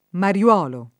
mariuolo [ mari U0 lo ] → mariolo